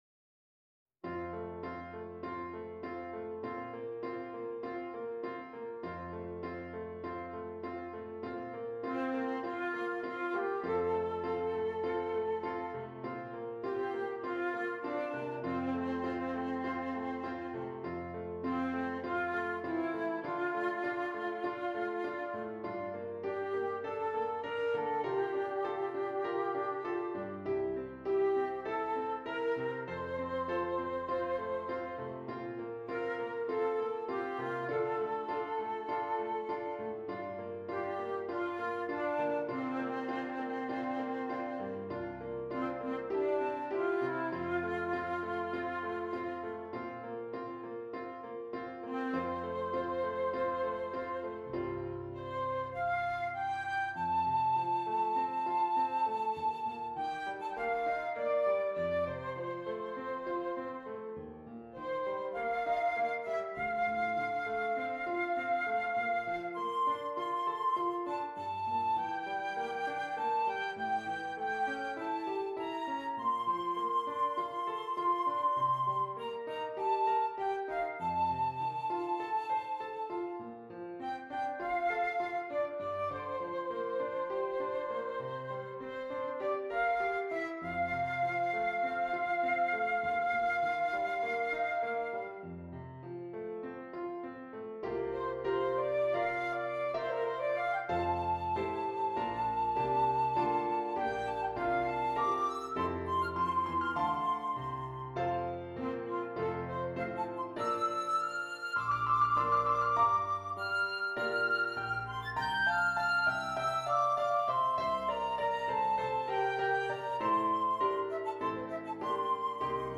Flute and Keyboard
Traditional